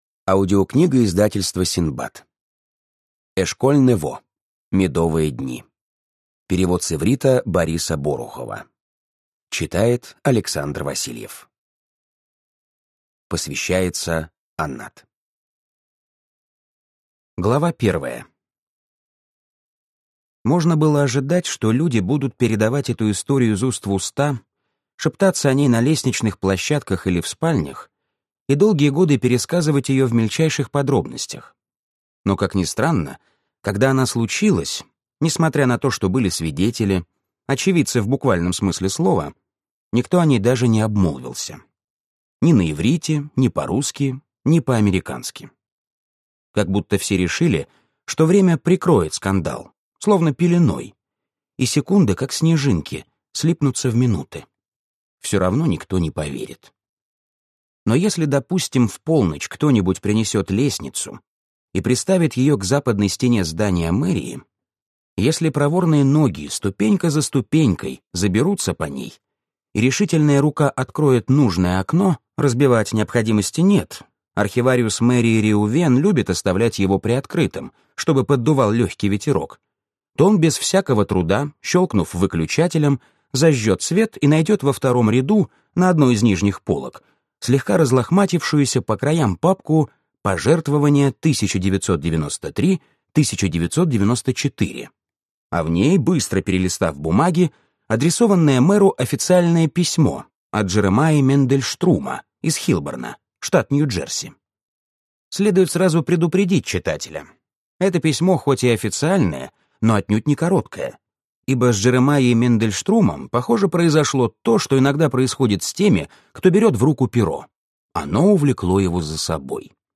Аудиокнига Медовые дни | Библиотека аудиокниг